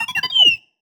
sci-fi_driod_robot_emote_16.wav